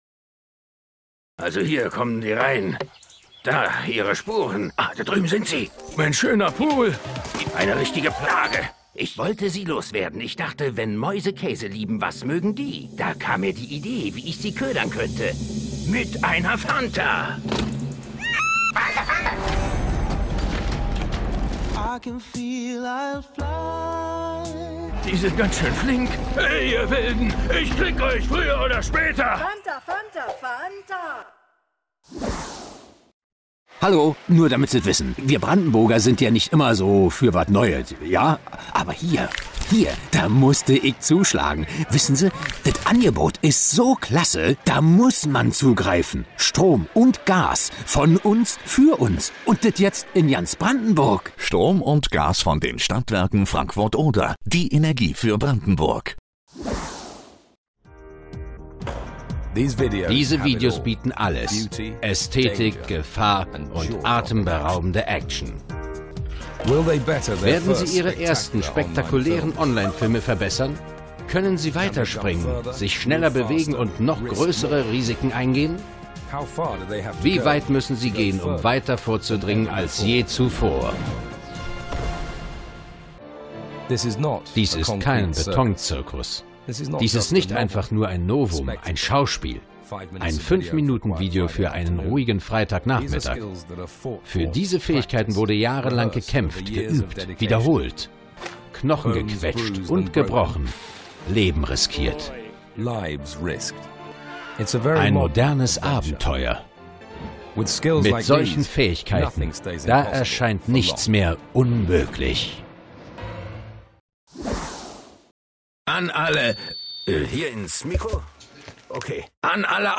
dunkel, sonor, souverän
Mittel plus (35-65)
Berlinerisch